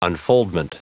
Prononciation du mot unfoldment en anglais (fichier audio)
Prononciation du mot : unfoldment